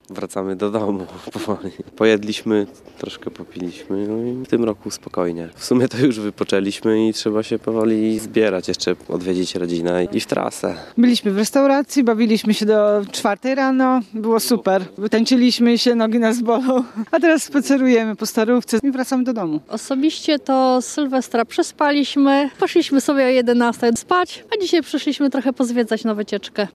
Zaraz zbieramy się w trasę, by odwiedzić rodzinę – przyznają spacerowicze.